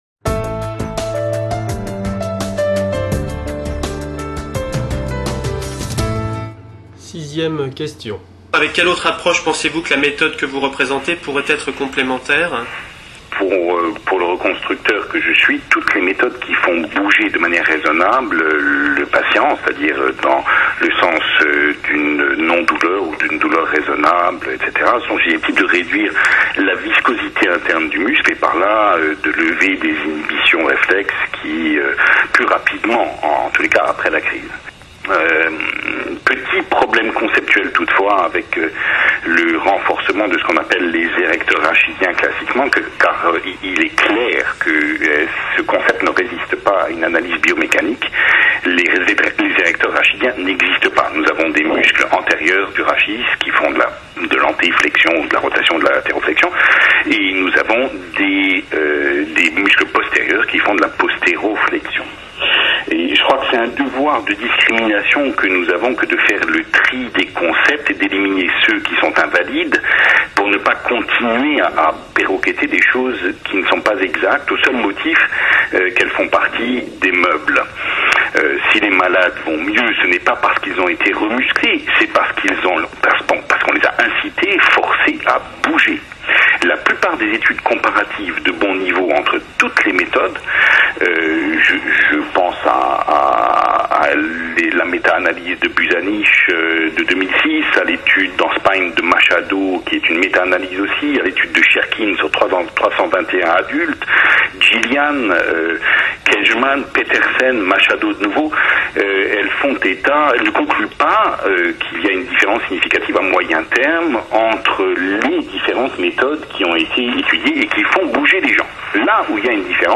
Débat confraternel: débat entre les 3 intervenants
Bienvenue à tous et à toute pour ce débat confraternel initié lors de la première journée de formation continue de Kinésithérapie, la revue qui s’est déroulée en janvier 2007.